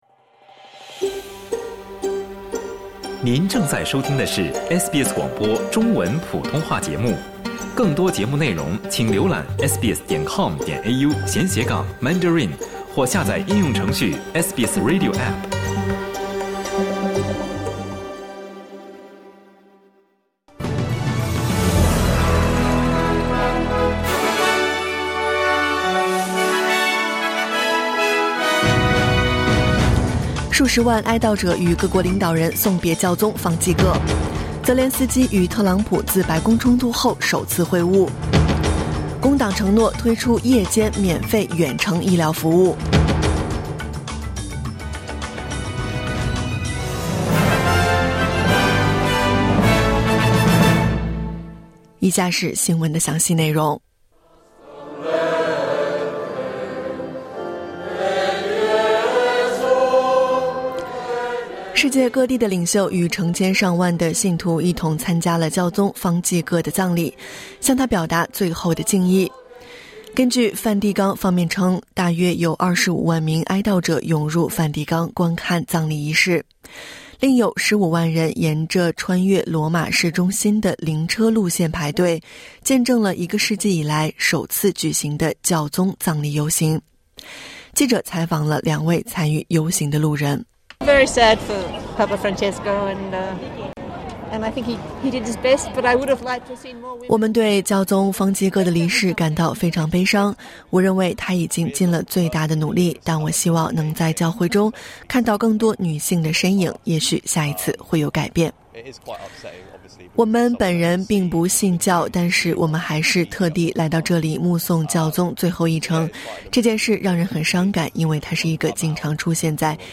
SBS早新闻（2025年4月27日）